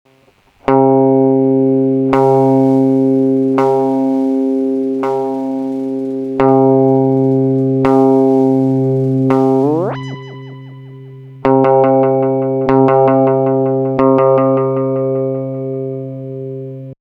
Появляется шум только во время повторения звука дилэем. То есть первый не обработанный звук чистый, а следующие за ним повторения шумят. Чем длиннее время задержки, тем сильнее шум. На короткой задержке шума нет, звучит чисто.
Прикрепил пример шума
lel_cz_noise.mp3